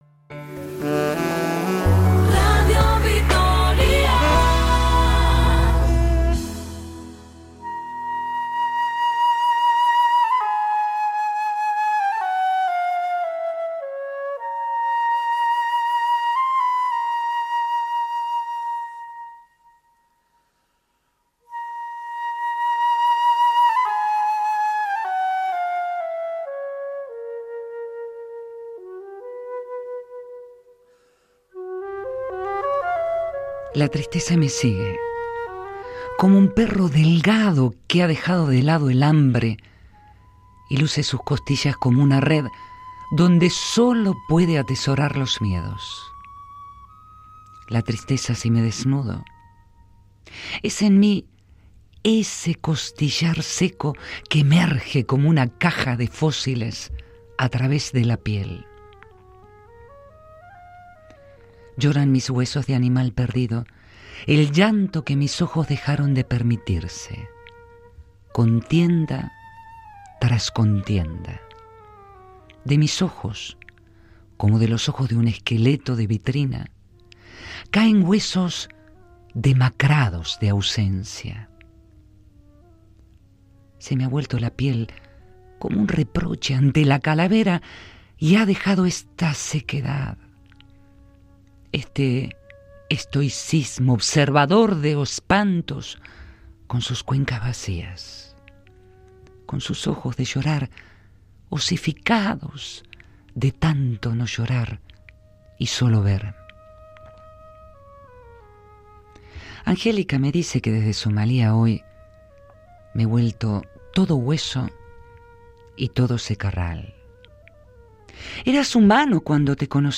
Radio Vitoria